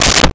Car Door Close 05
Car Door Close 05.wav